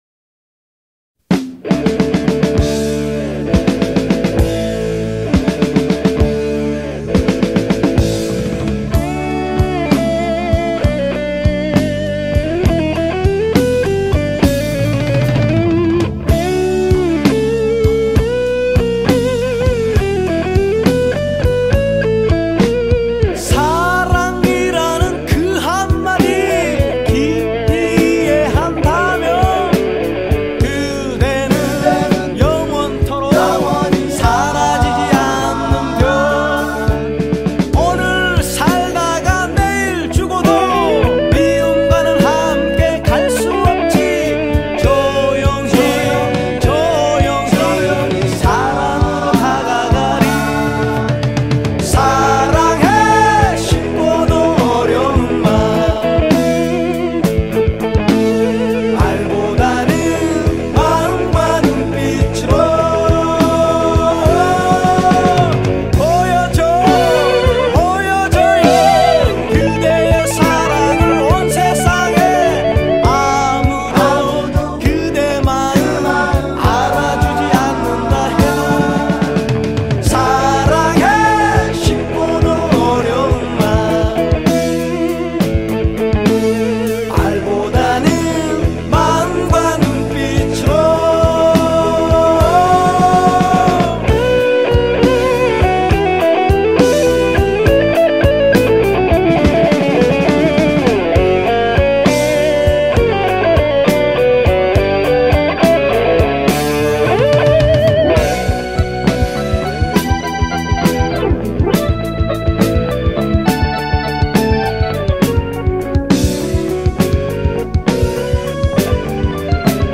한국적인 록을 표현한 4인조 록 밴드였다.
메인 보컬 사이에 등장하는 기타 솔로가 훌륭하다.
딥 퍼플 스타일의 리프를 만들며 등장했다.
소 울음소리로 시작하는 다섯 번째 곡